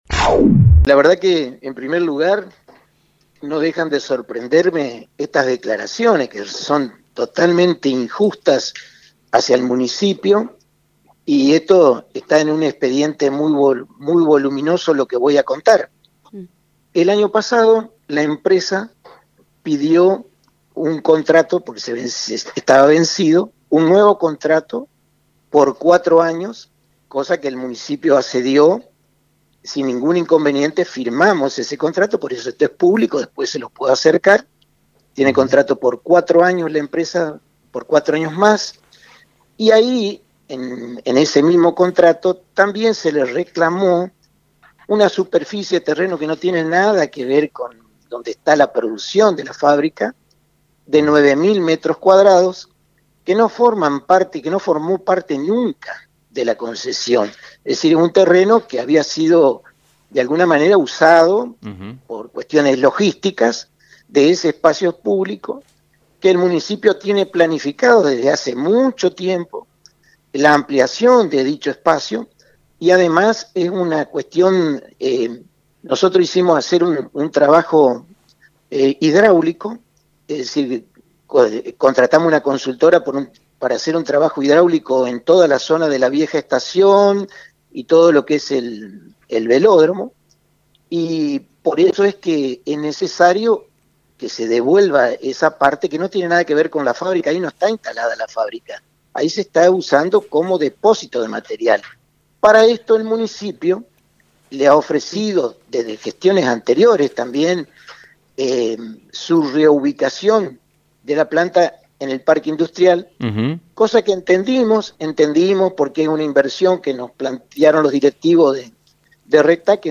A su vez, el intendente Domingo Maiocco también realizó declaraciones en la mencionada emisora, donde expresó su punto de vista y declaró sentirse dolido ante el tratamiento que tuvo la noticia.